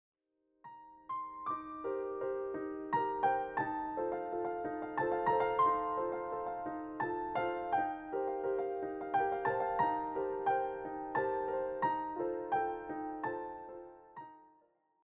all arranged and performed as solo piano pieces.